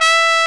TRUMPET 1 E4.wav